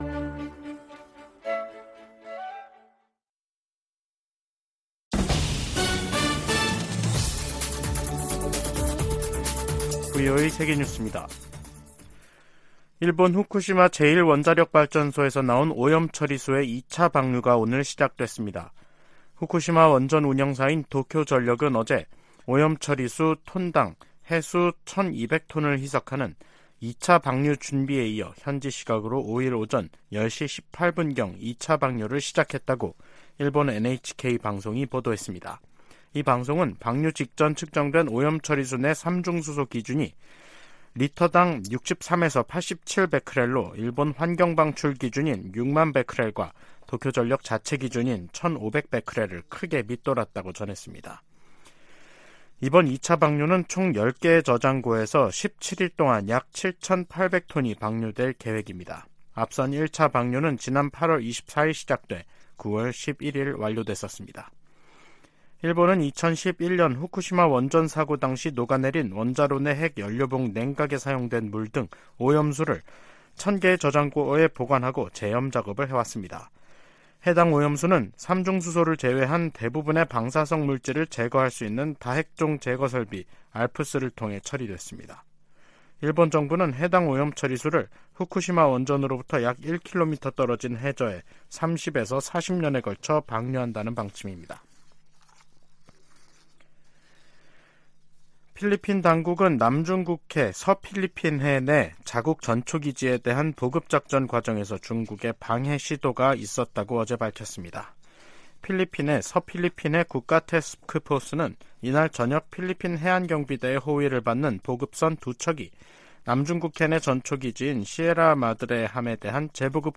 VOA 한국어 간판 뉴스 프로그램 '뉴스 투데이', 2023년 10월 5일 2부 방송입니다. 로이드 오스틴 미 국방장관과 기하라 미노루 일본 방위상이 북한의 도발과 중국의 강압, 러시아의 전쟁을 미-일 공통 도전으로 규정했습니다. 미국 국무부가 제재 대상 북한 유조선이 중국 영해에 출몰하는 데 대해, 사실이라면 우려한다는 입장을 밝혔습니다. 한국 헌법재판소가 대북전단금지법에 위헌 결정을 내리면서 민간단체들이 살포 재개 움직임을 보이고 있습니다.